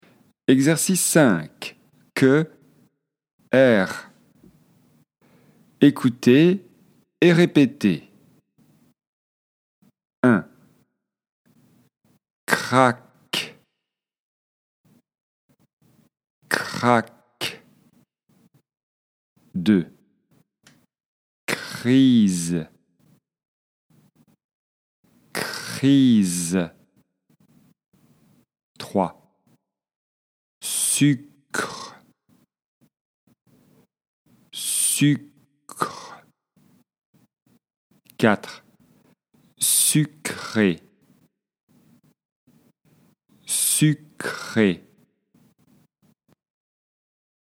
Prononciation française : consonnes suivies de R
📌 Écoutez et répétez :